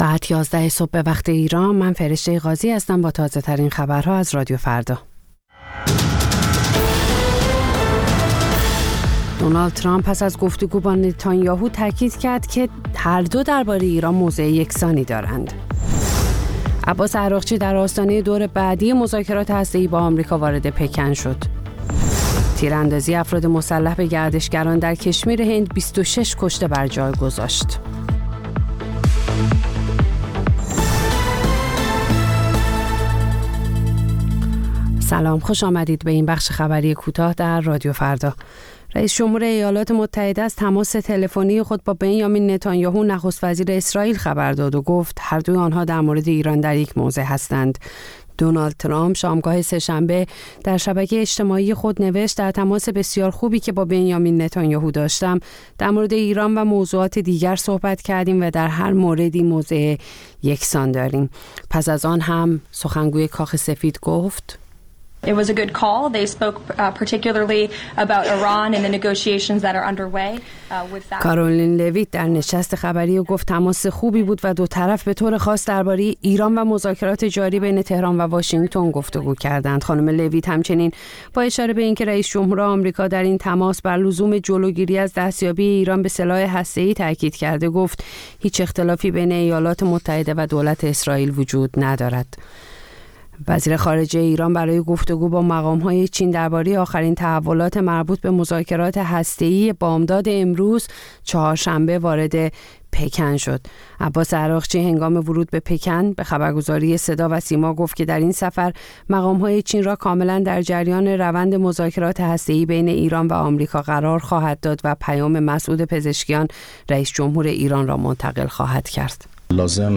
سرخط خبرها ۱۱:۰۰